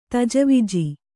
♪ tajaviji